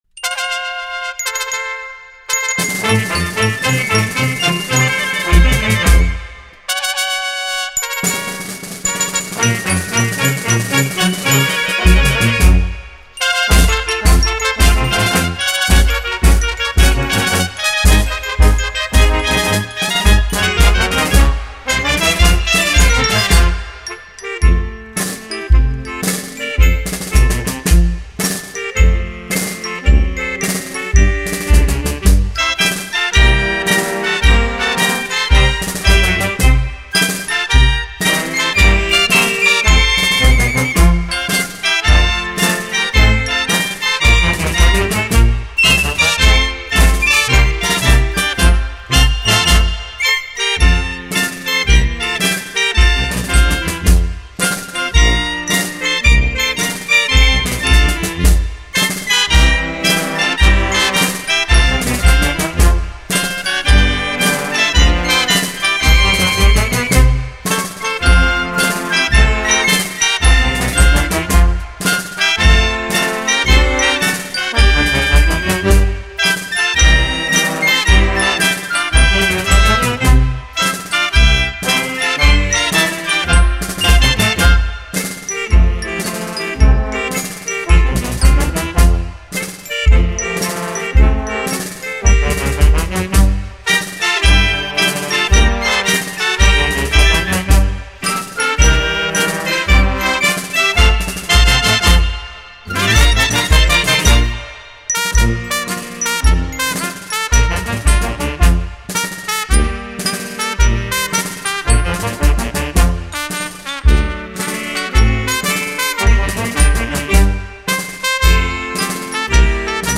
1.2 Himno de la Caballería Blindada (Instrumental)
hcb-himno-de-la-caballeria-blindada-ecuatoriana-instrumental-ok.mp3